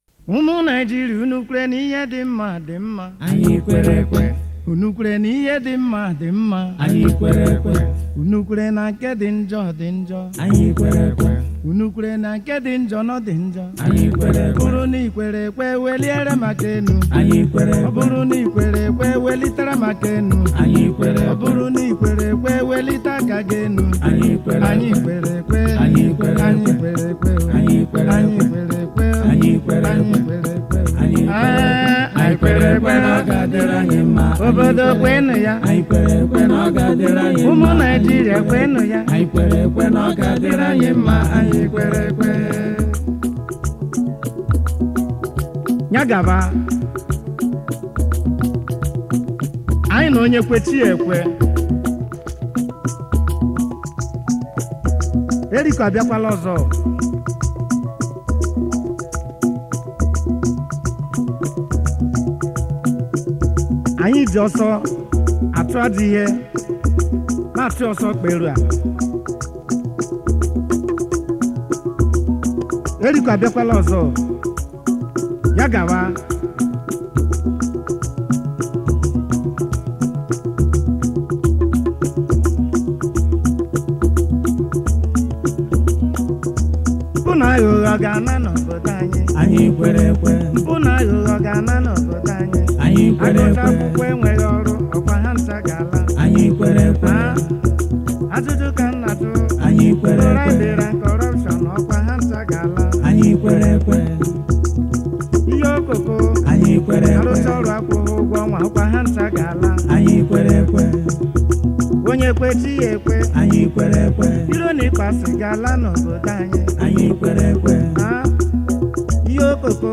Ogene
Bongo/Highlife